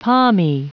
Prononciation du mot palmy en anglais (fichier audio)
Prononciation du mot : palmy